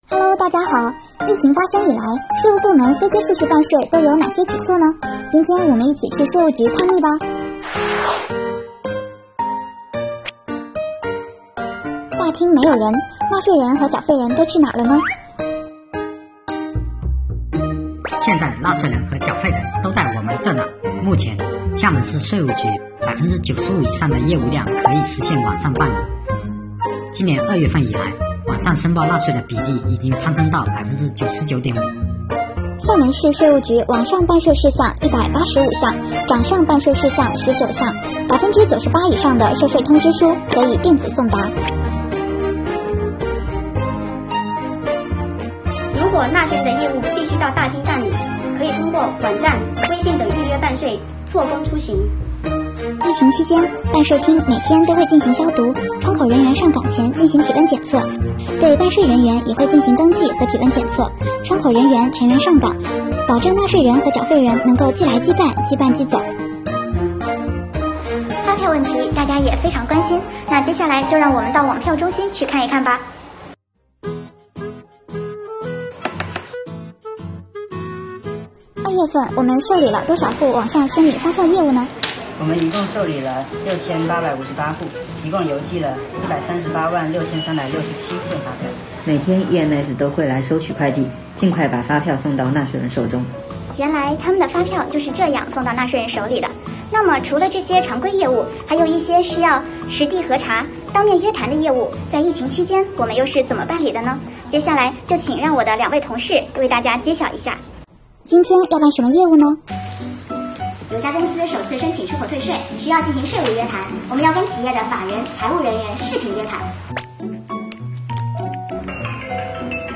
税务小姐姐带你揭秘↓↓↓